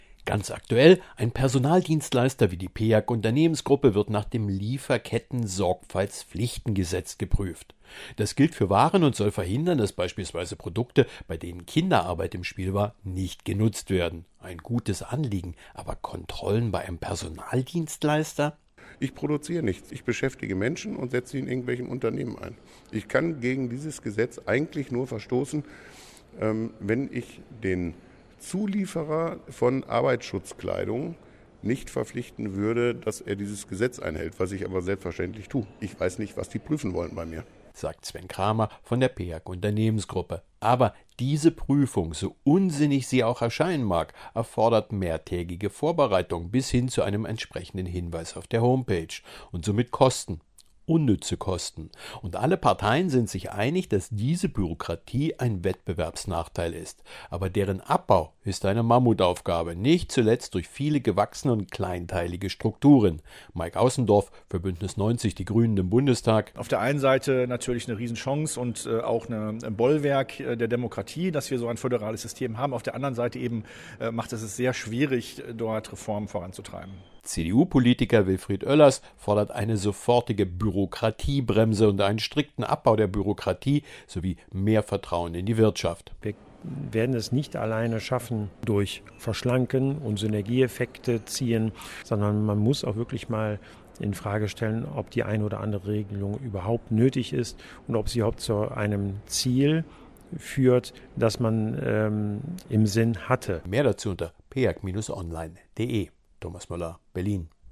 Für die Personaldebatten produzieren wir jeweils Presseinfos, O-Töne und einen sendefertigen Radiobeitrag zum kostenfreien Download.